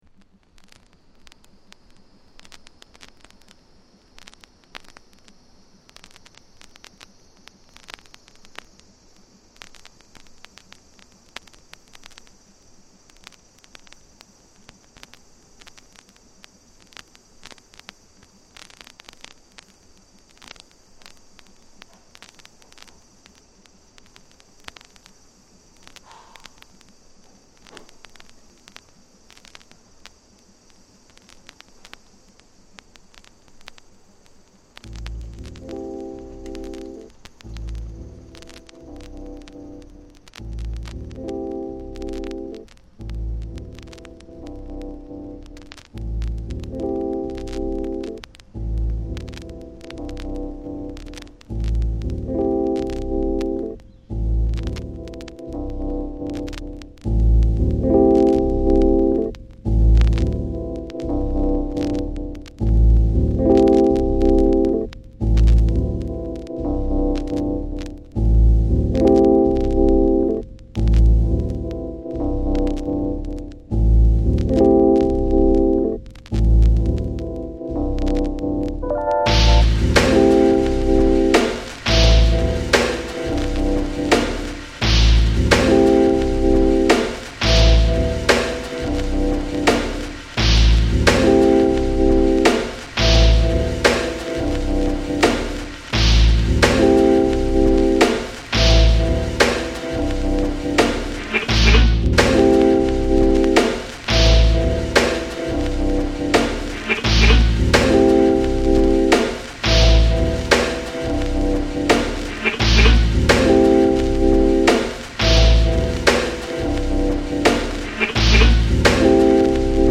極めて前衛的でエクスペリメンタルな5トラック。